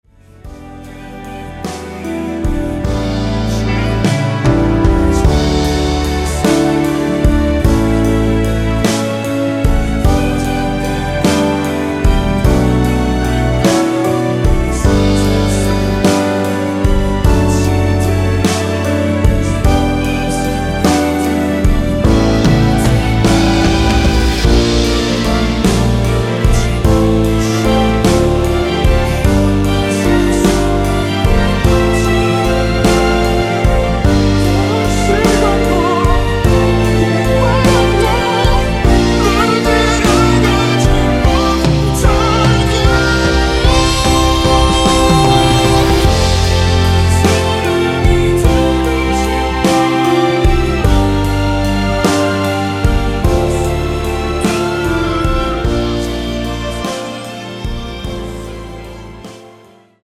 원키에서(-3)내린 멜로디와 코러스 포함된 MR입니다.(미리듣기 확인)
앞부분30초, 뒷부분30초씩 편집해서 올려 드리고 있습니다.
중간에 음이 끈어지고 다시 나오는 이유는